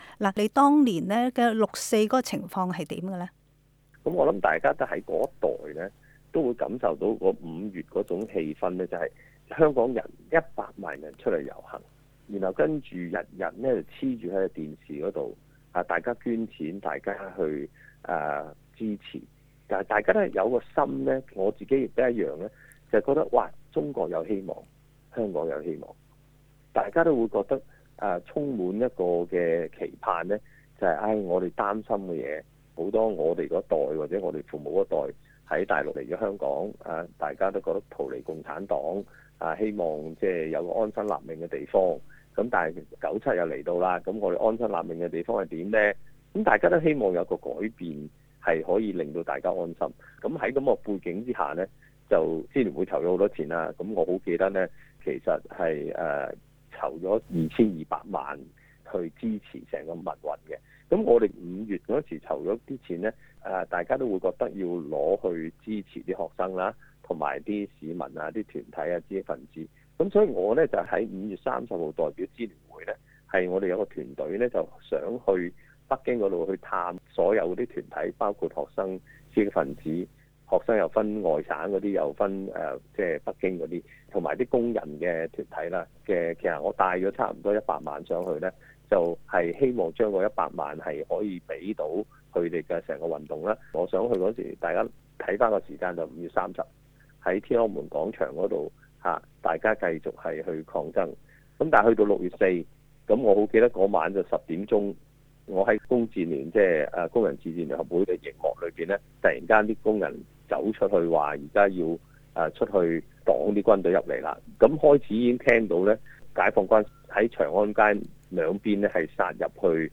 香港支聯會主席李卓人對本台廣東話節目表示，其實香港的限聚令是直到6月4日，超過8個人的集會就會清場。